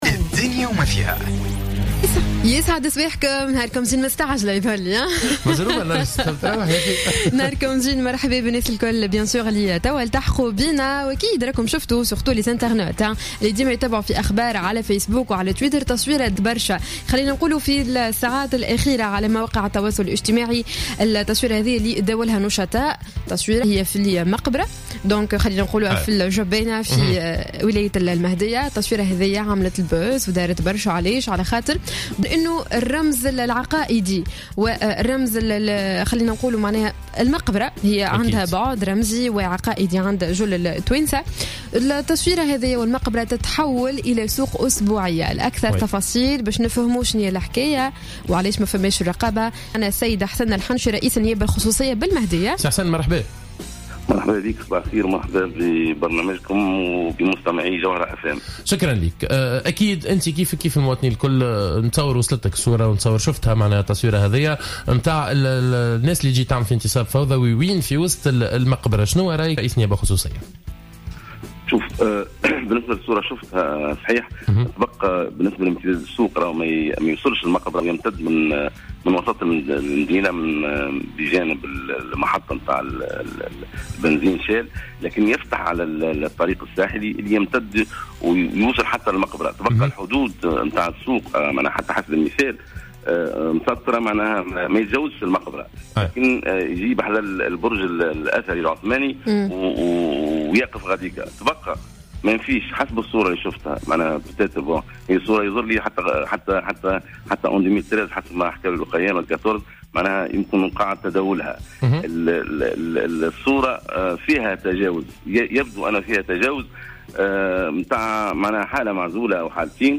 أكد رئيس النيابة الخصوصية بالمهدية حسن الحنشي في مداخلة له على الجوهرة "اف ام" صباح اليوم الثلاثاء 5 جانفي 2015 أن الصورة المتداولة لسوق داخل مقبرة المهدية ليست جديدة وهي تعود لسنة 2014 ولكن تم اعادة نشرها وتداولها وفق قوله.